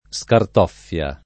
vai all'elenco alfabetico delle voci ingrandisci il carattere 100% rimpicciolisci il carattere stampa invia tramite posta elettronica codividi su Facebook scartoffia [ S kart 0 ff L a ] s. f. — usato quasi solo nel pl. scartoffie